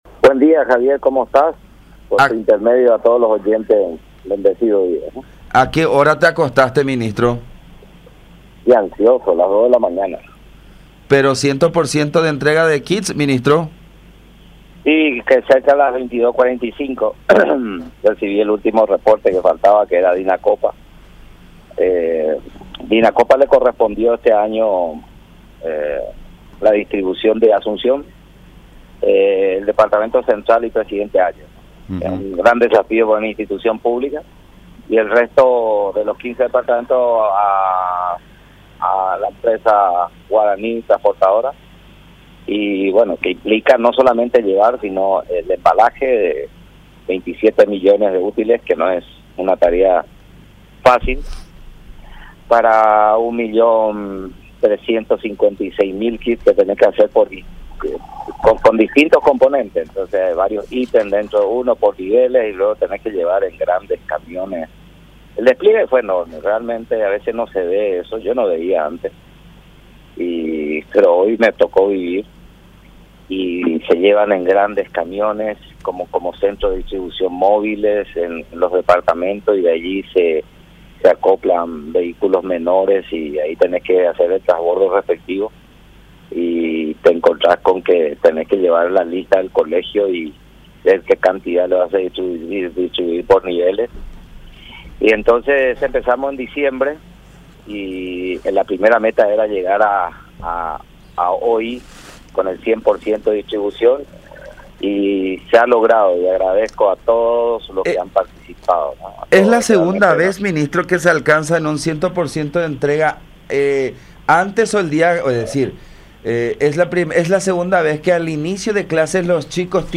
Esos son los registros históricos que tenemos”, indicó en comunicación con La Unión.
02-MINISTRO-EDUARDO-PETTA.mp3